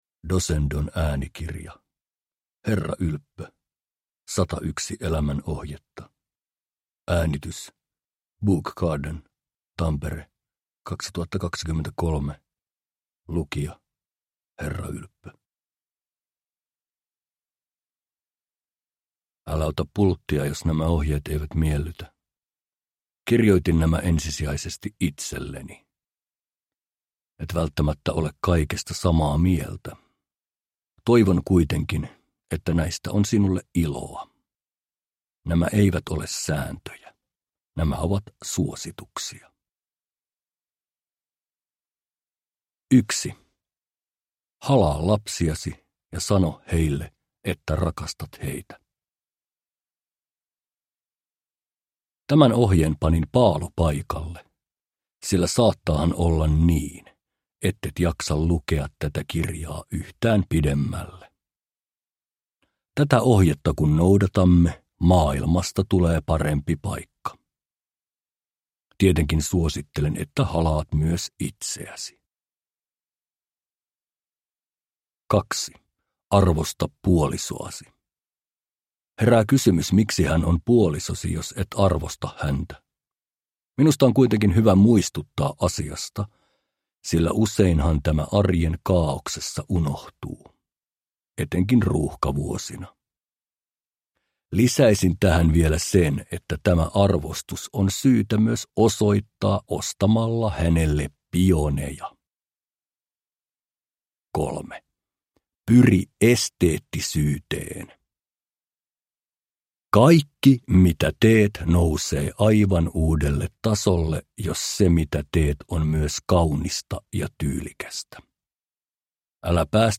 Uppläsare: Herra Ylppö